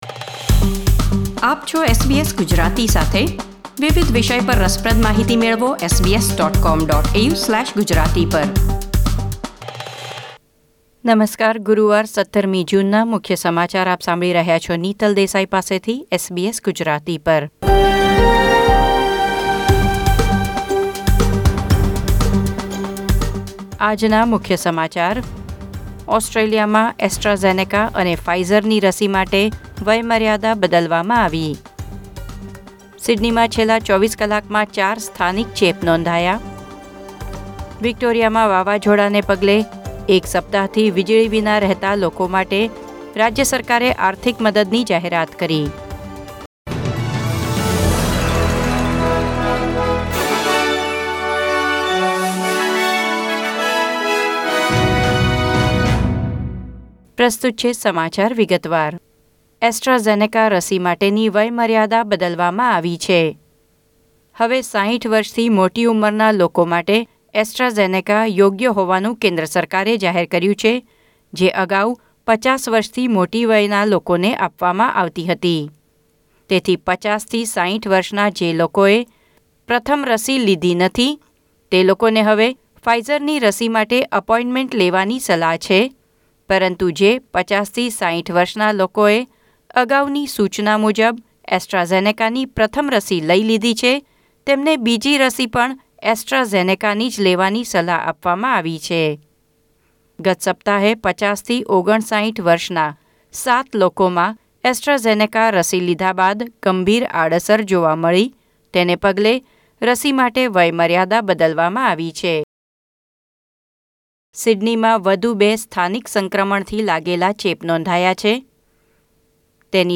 SBS Gujarati News Bulletin 17 June 2021